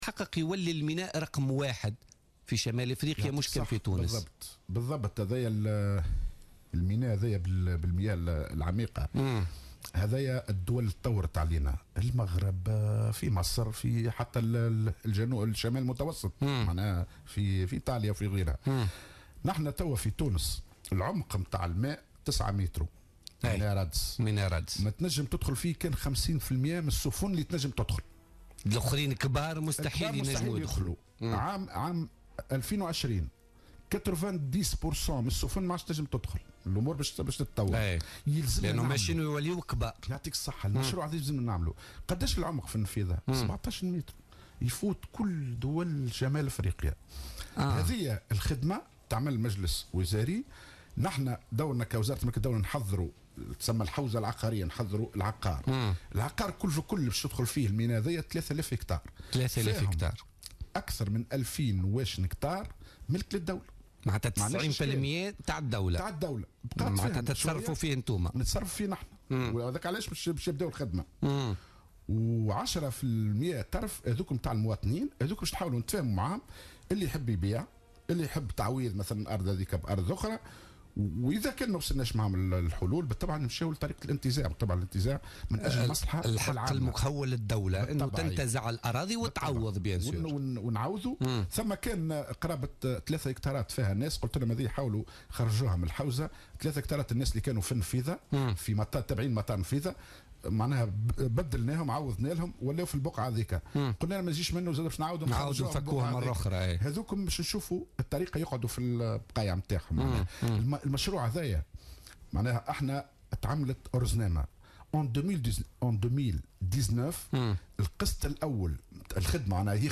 أكد وزير أملاك الدولة والشؤون العقارية حاتم العشي ضيف بوليتيكا اليوم الجمعة 18 مارس 2016 أن أملاك الأجانب إنتهى عمرها الإفتراضي وكان من المفترض أن يتم هدمها منذ 20 سنة مضت وكان يفترض أن يتم تأهيلها والإستفادة منها على حد قوله.